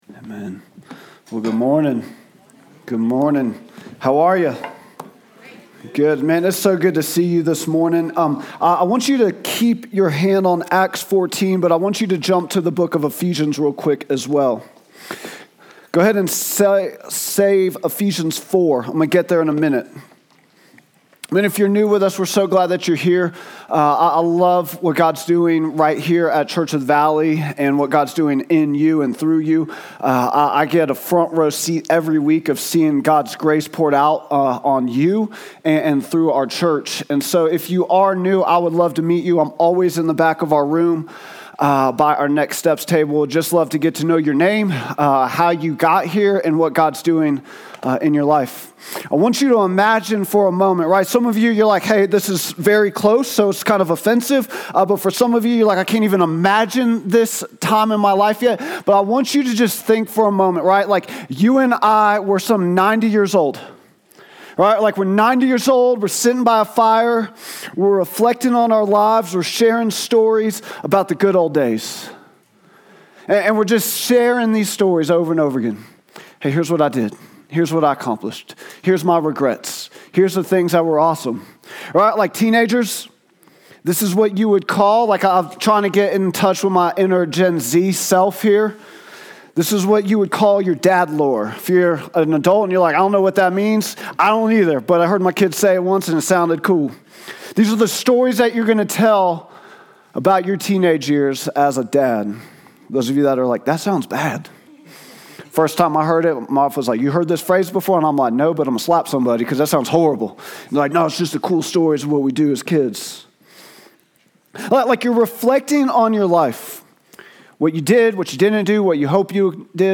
Vision & Values Meet Our Team Statement of Faith Sermons Contact Us Give To the Ends of the Earth | Acts 14:24-28 February 15, 2026